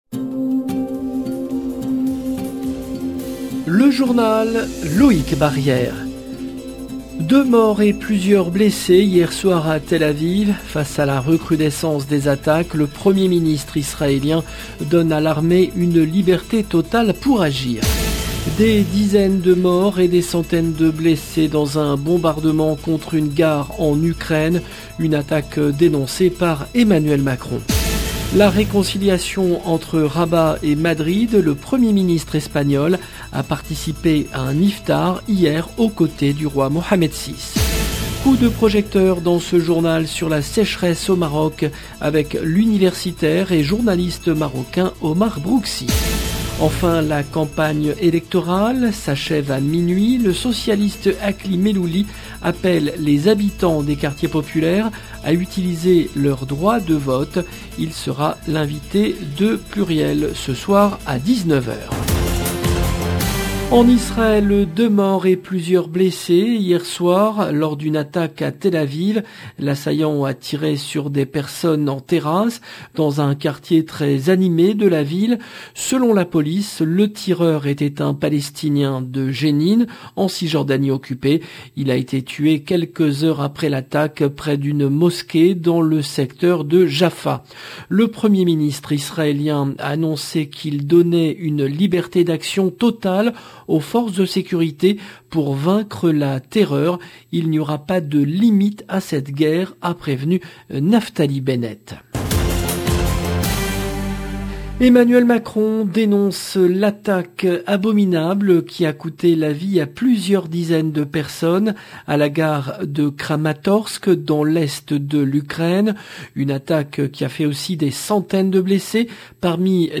LE JOURNAL DU SOIR EN LANGUE FRANCAISE DU 8/04/22